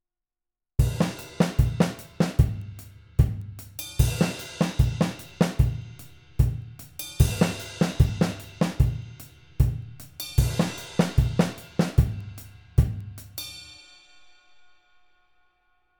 First of all let’s mark the beginning of the phrase with a single crash hit and the end with a single ride bell hit